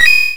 pickup_coin_4.wav